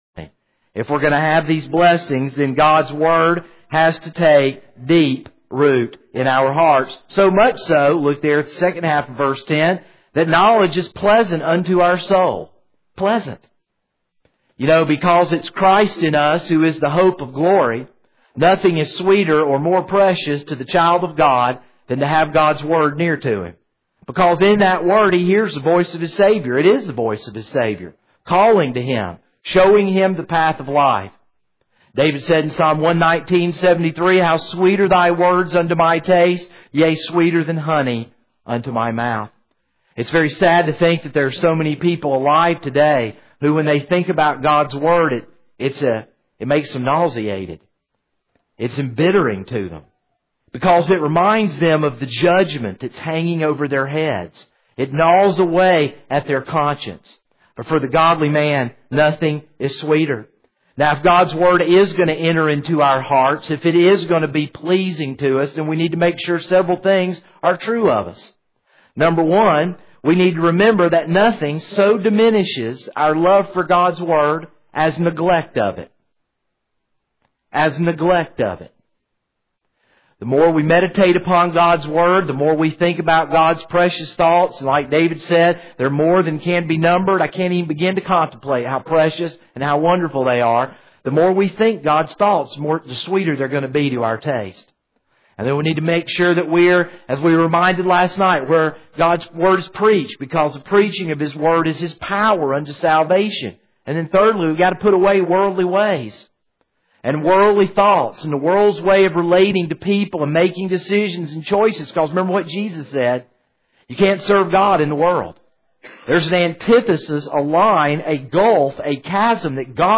This is a sermon on Proverbs 2:10-22.